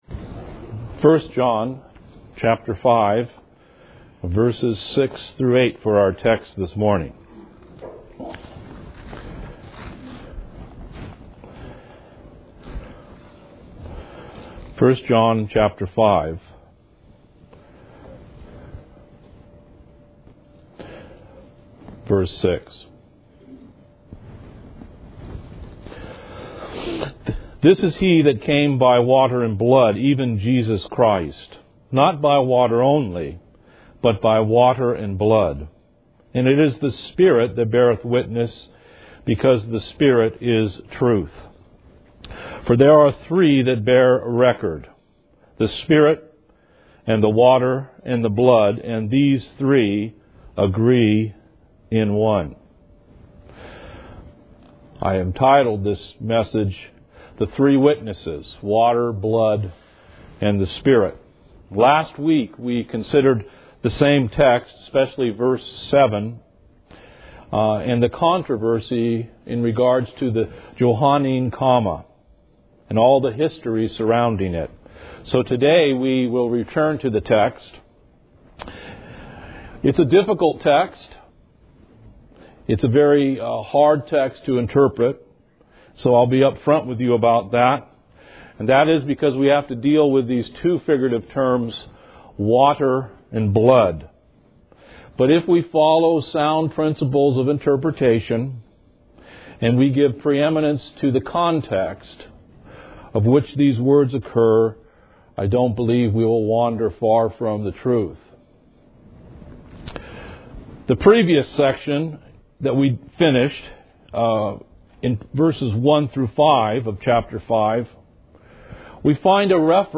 Sermons - Sovereign Grace Baptist Church of Silicon Valley